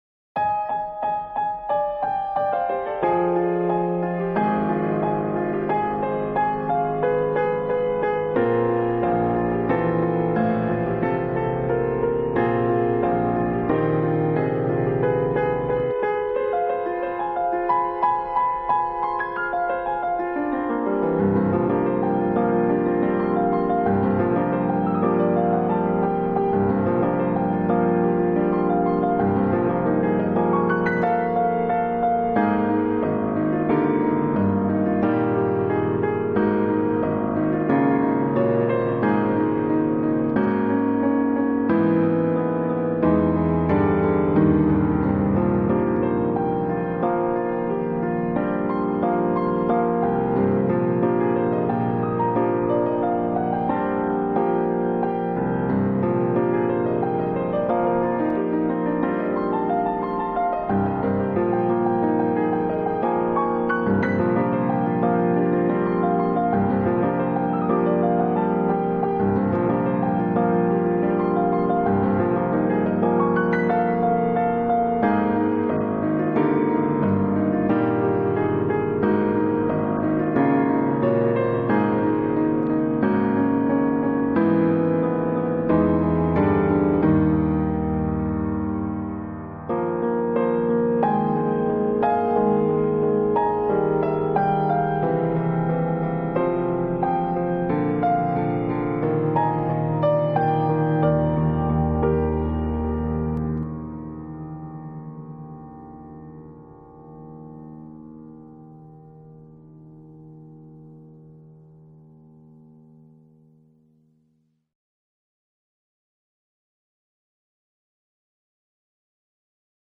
The second movement serves as the expected contrasting movement of the sonata, carrying the last motif of the first movement into the second in rigid time with a more purposeful direction.